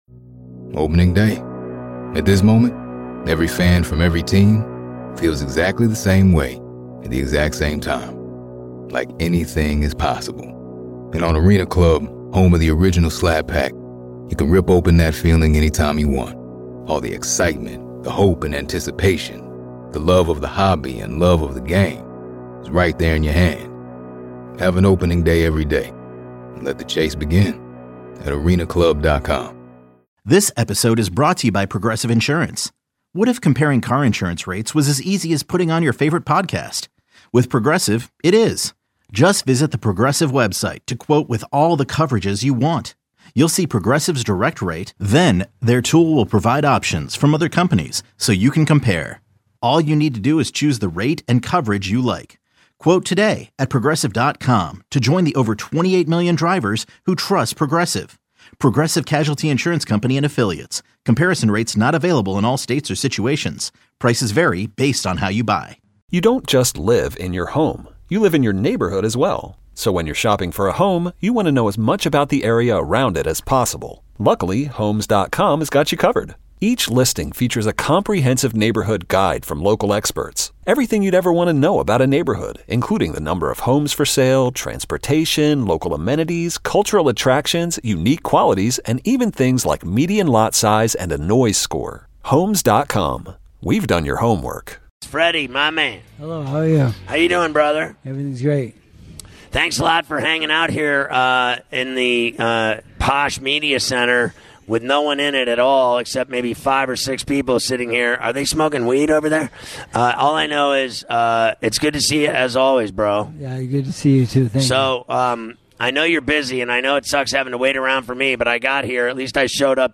11-21-19 - Ferrall On The Bench - Freddie Roach Interview